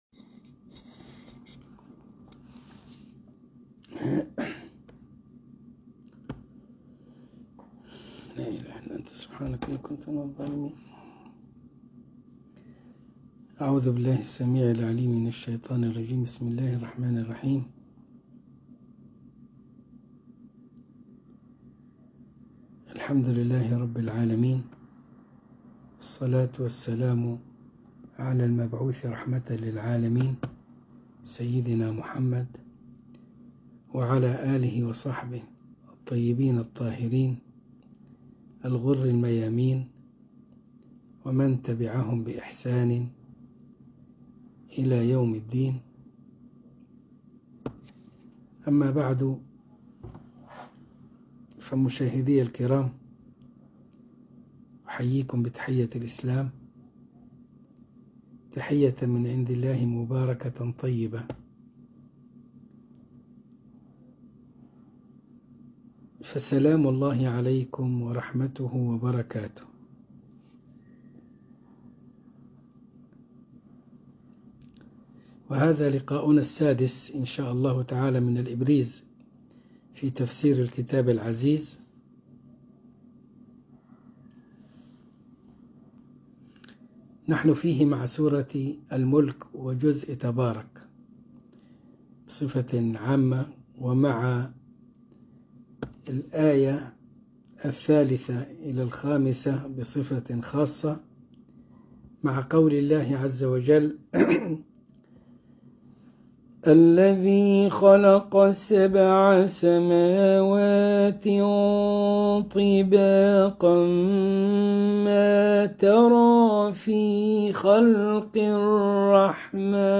الدرس السادس من الإبريز في تفسير الكتاب العزيز سورة الملك آية ٣-٥